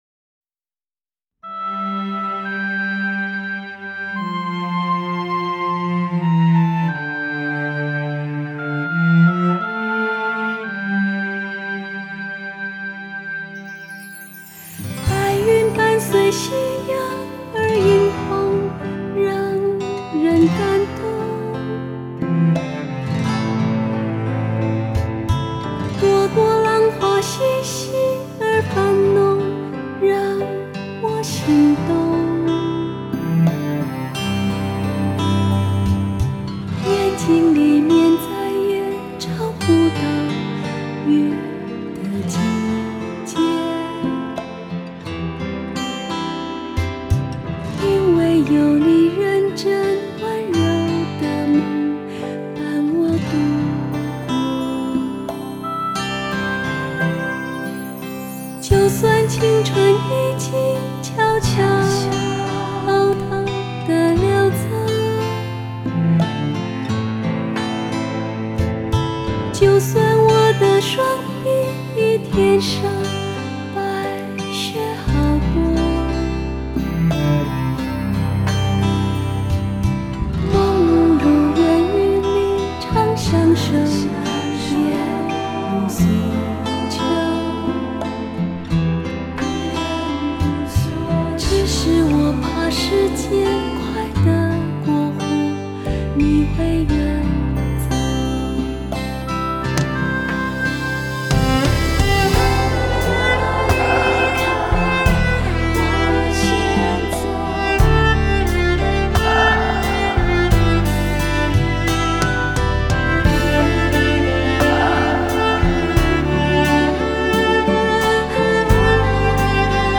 如今这位漂亮宝贝更是全力以赴进军国语歌坛，优雅的气质、甜美的外形、独特的嗓音让她在歌坛大放异彩！